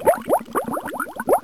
spinnerspin.wav